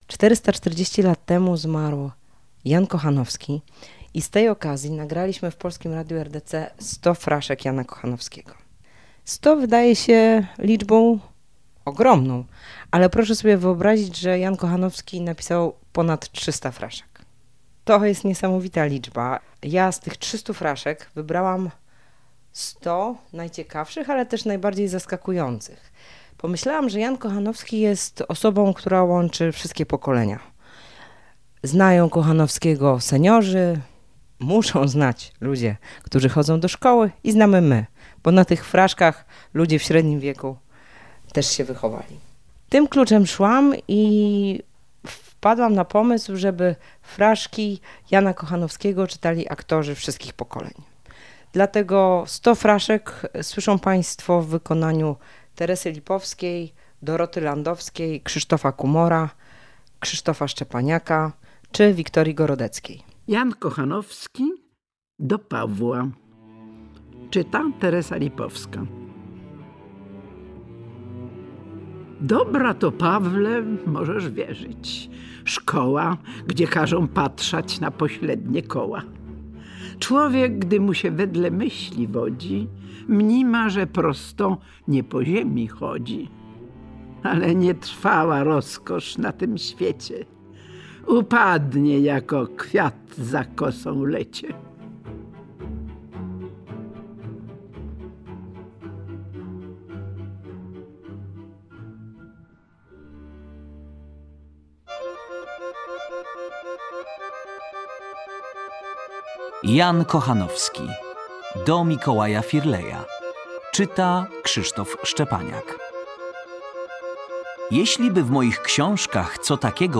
Występują aktorzy wszystkich pokoleń